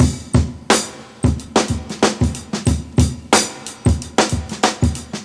Free drum loop sample - kick tuned to the E note. Loudest frequency: 939Hz
• 91 Bpm Drum Groove E Key.wav
91-bpm-drum-groove-e-key-LMY.wav